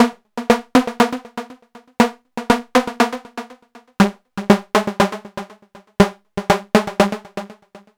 TSNRG2 Lead 010.wav